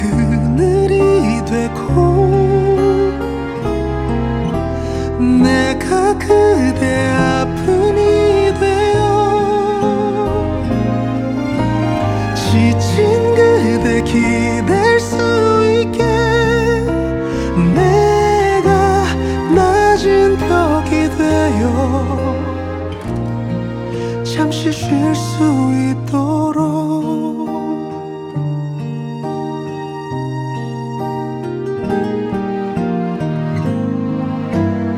K-Pop
Жанр: Поп музыка / Соундтрэки